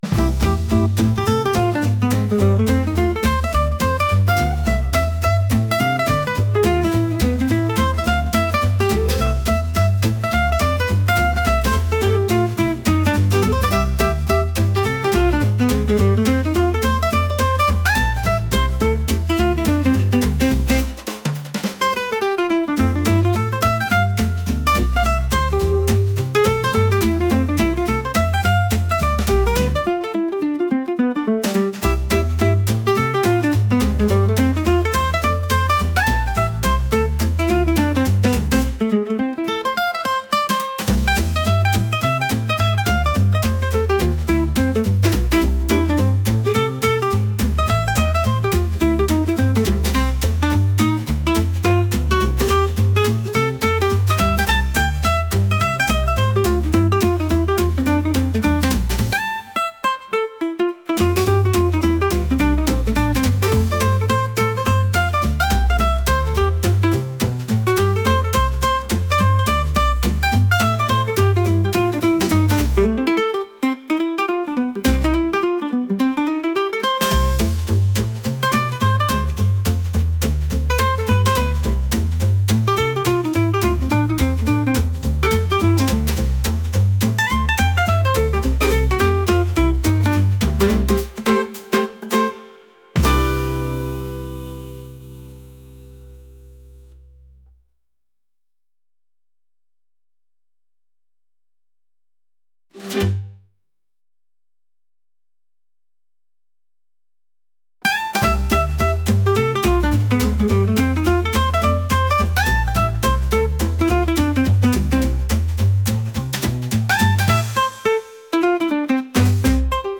energetic | jazz